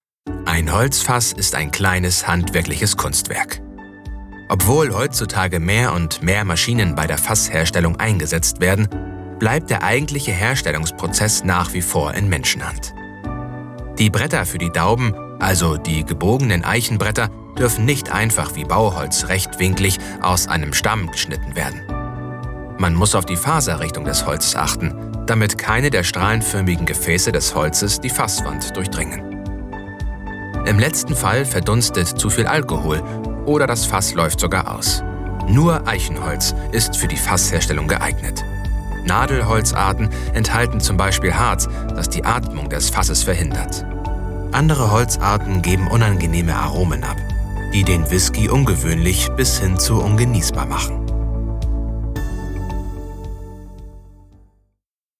Commercial, Deep, Natural, Cool, Warm
Audio guide